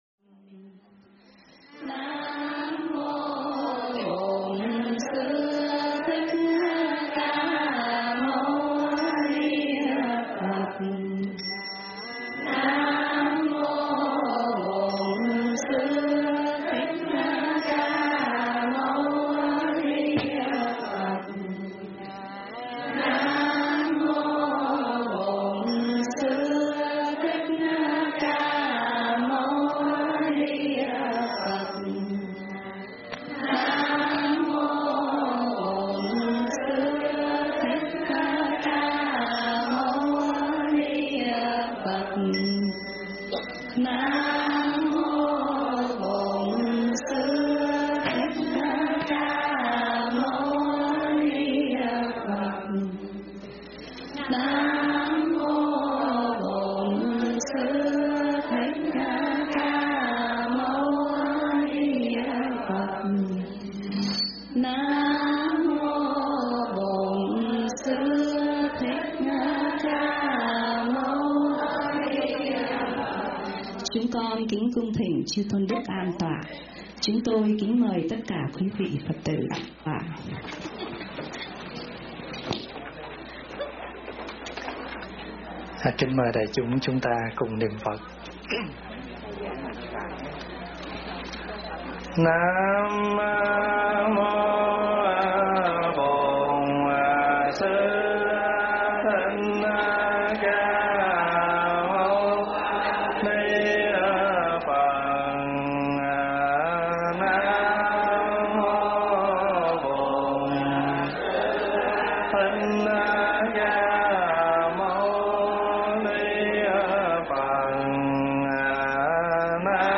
Nghe Mp3 thuyết pháp Tâm An Lạc
Nghe mp3 pháp thoại Tâm An Lạc